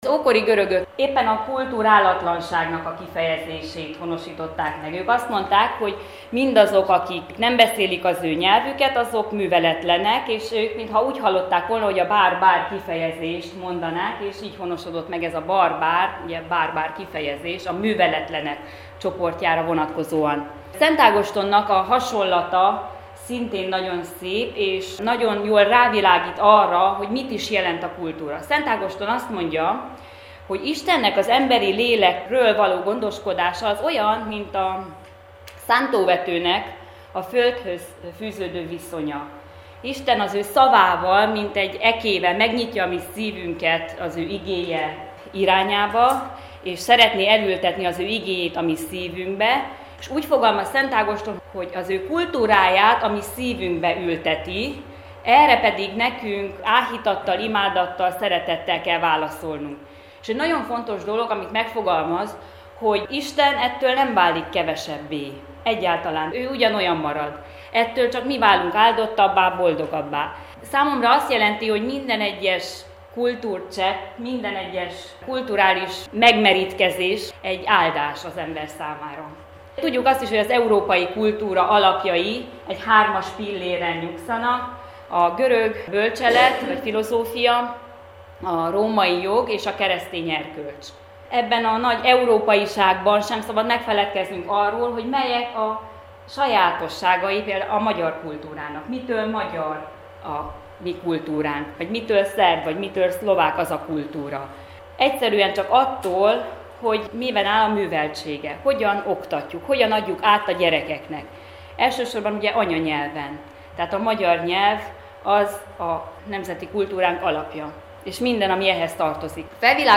A pedagógusok kultúraközvetítő szerepének XXI. századi lehetőségeiről, a kisebbségi történelem tanterv kidolgozásáról, a tanár-diák viszonyának alakulásáról tartott előadást a Calvineum református templomban a Koós Ferenc kör decemberi utolsó összejövetelén